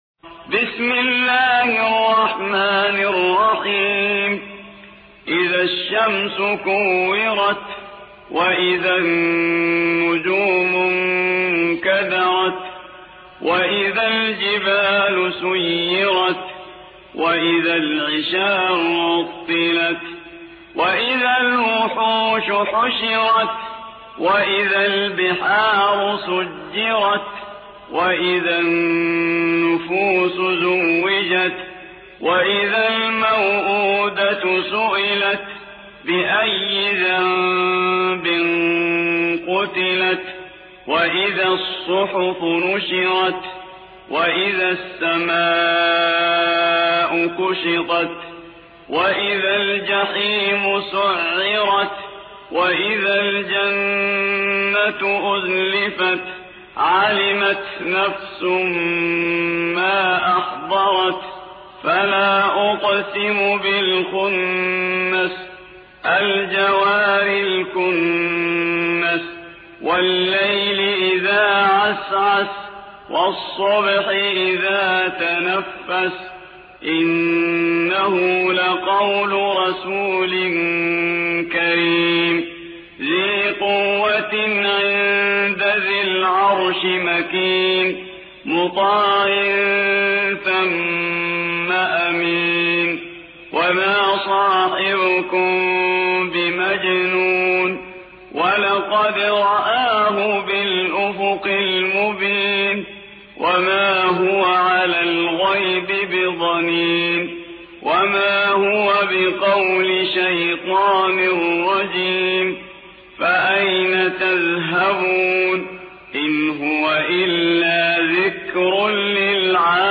81. سورة التكوير / القارئ